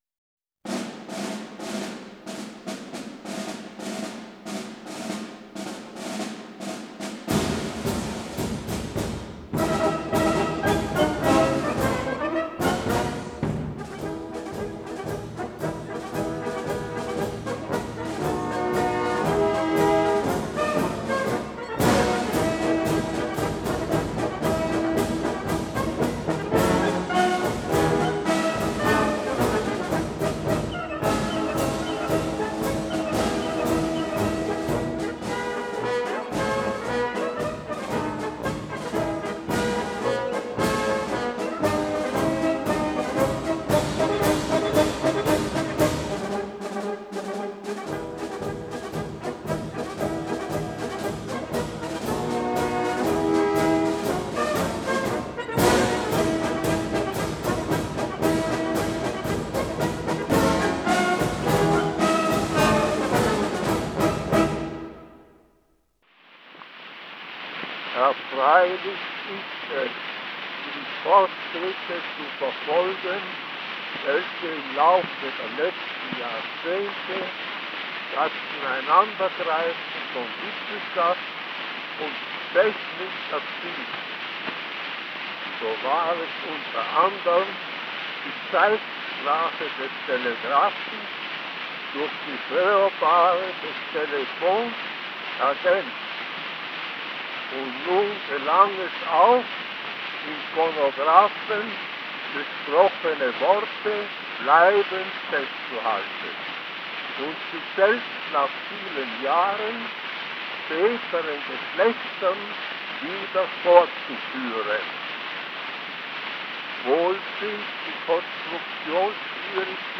Begins and ends with march music. Speech given by Austrian Emperor Franz-Josef I in Bad Ischl on the importance of the phonograph.